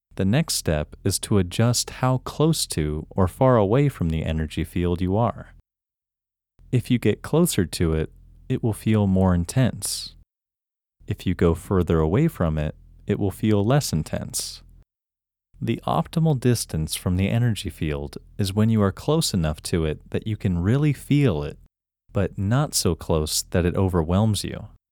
IN – First Way – English Male 6
IN-1-English-Male-6.mp3